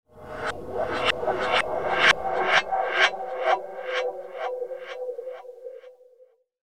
Звуки кошмара
Призрак пропадает